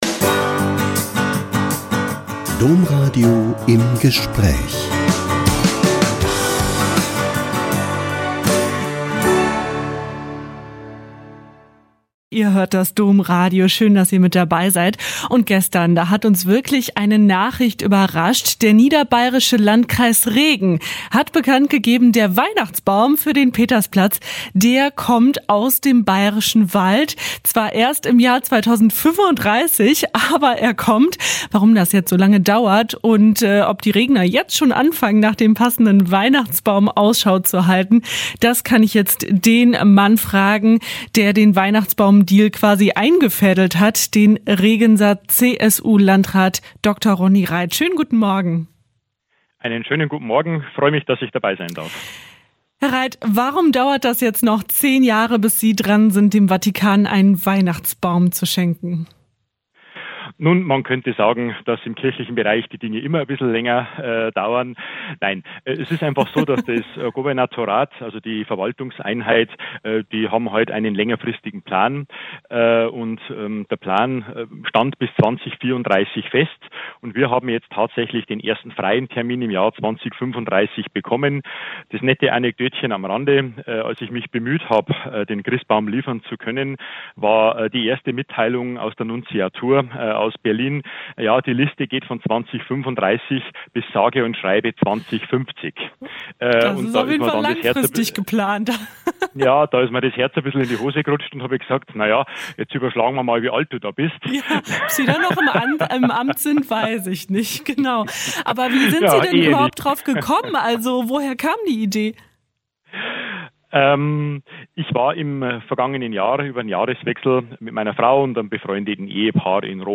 Der Landrat Ronny Reit erzählt, wie die Idee